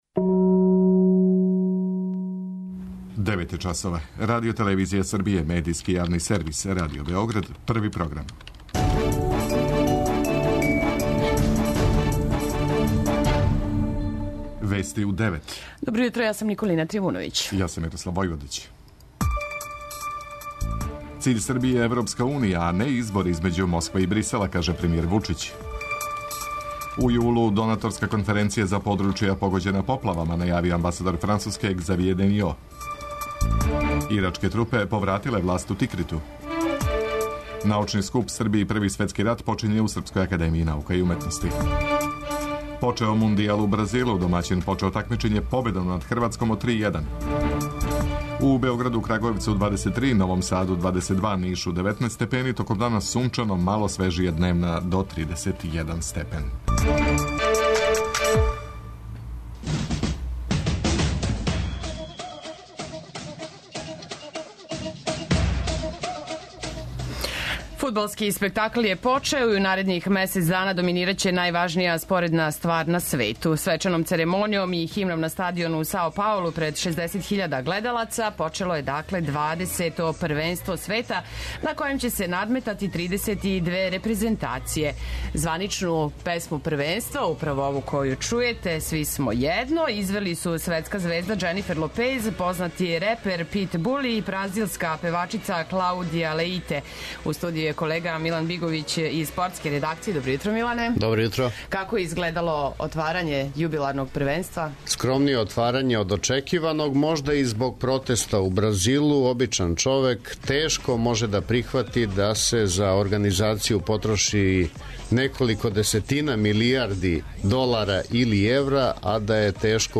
Вести уређују и воде